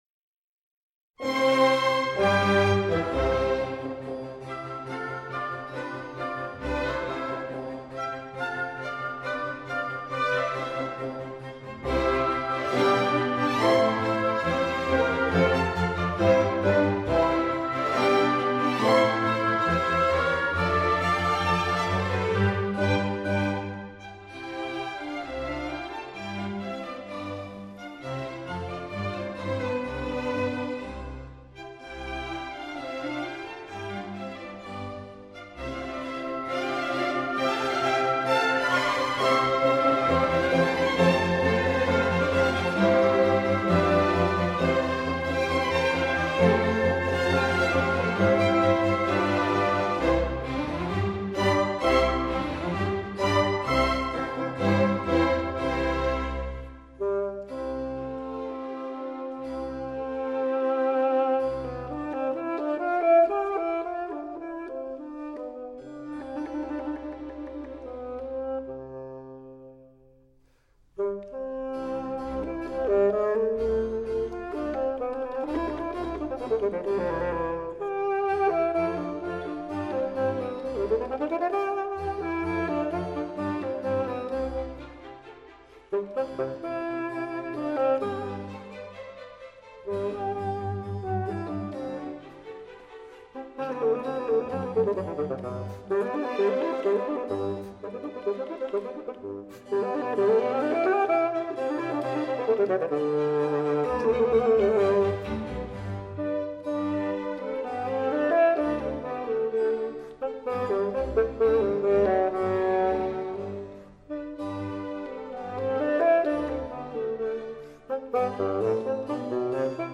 allegro moderato
os fagotistas usam fagotes no sistema francês